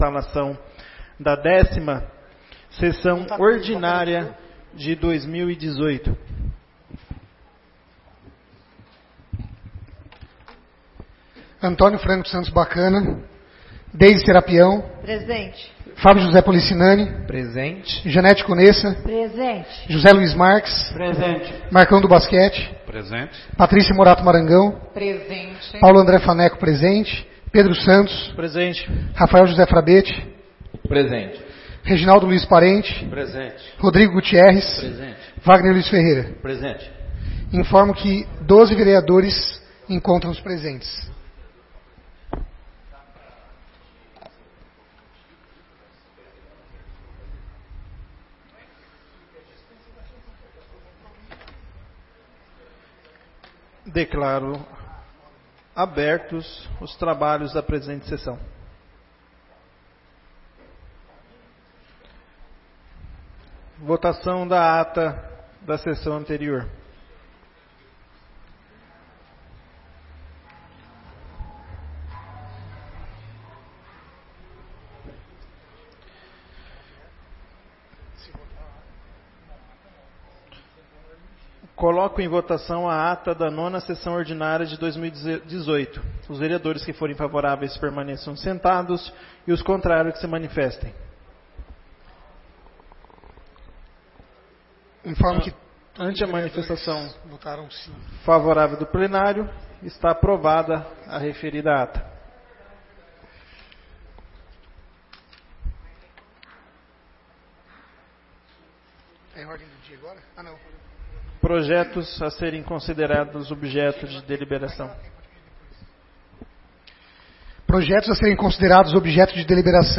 10ª Sessão Ordinária de 2018